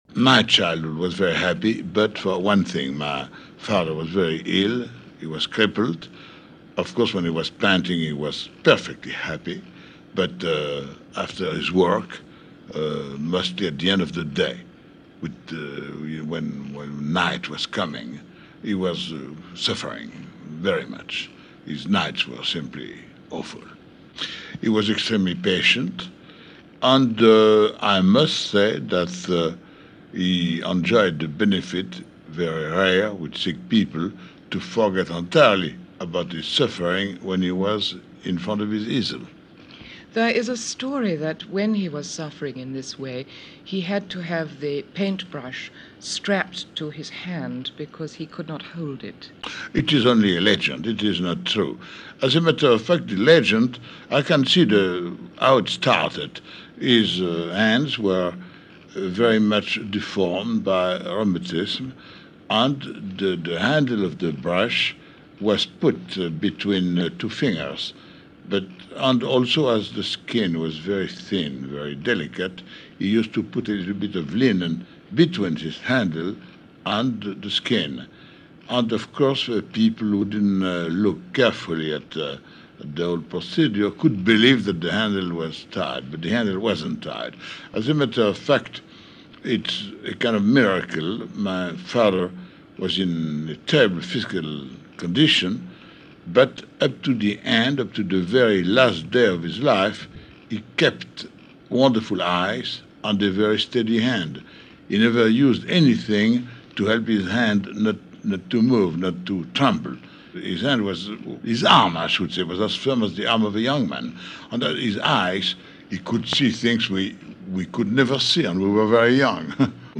In this interview, conducted by the BBC in the early 1960s, Jean is asked about his father’s work, what he was like to be around and his dread of vacations. Sadly, the interview is only 4 minutes long and was part of a series of Short Interview Talks assembled by the BBC Transcription Service as program fillers for radio stations around the world.
BBC-Jean-Renoir-Interview.mp3